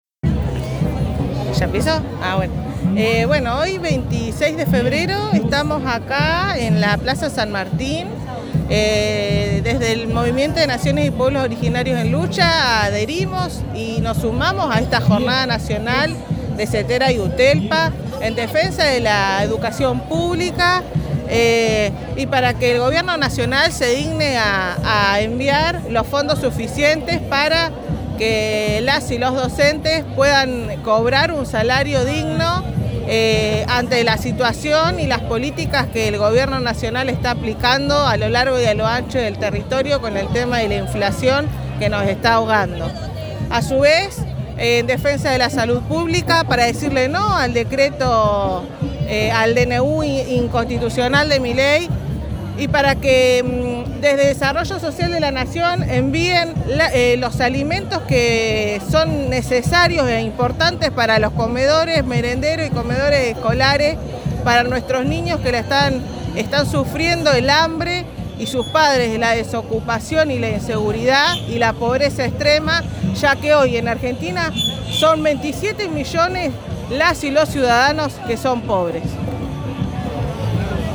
Escucha los relatos en primera persona de quienes asistieron!